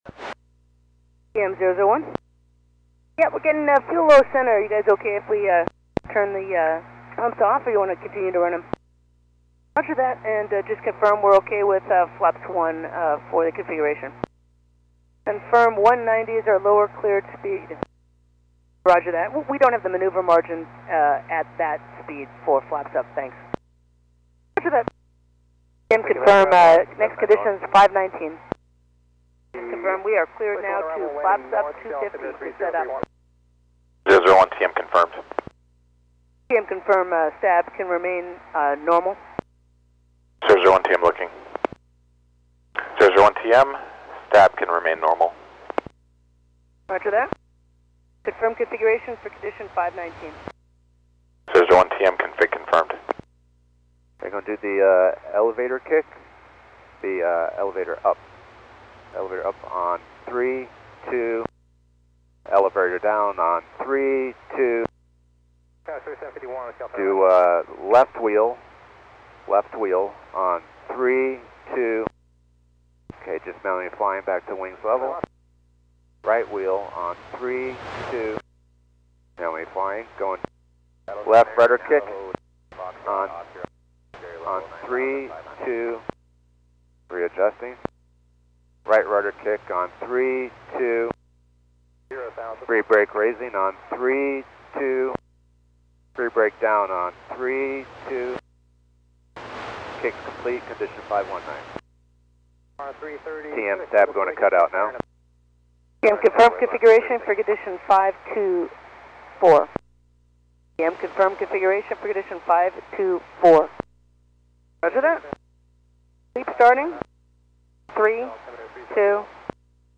Comms with the telemetry room